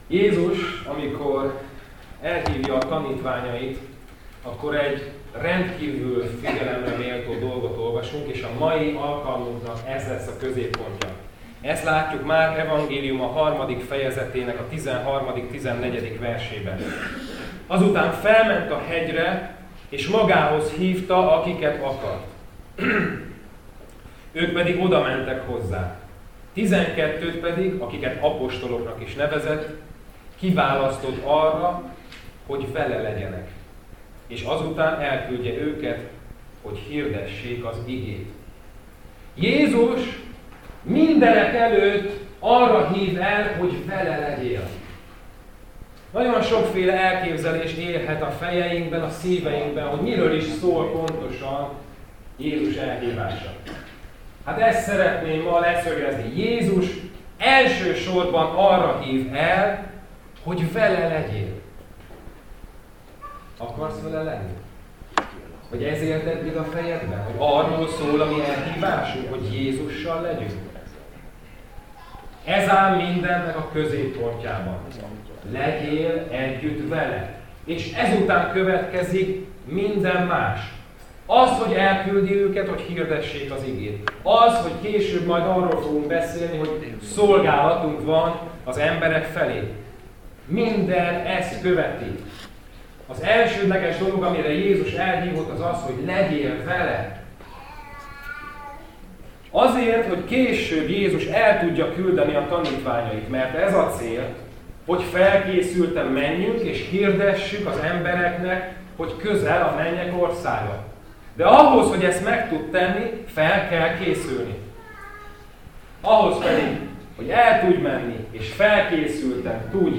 Passage: Márk 3, 13-14 Service Type: Tanítás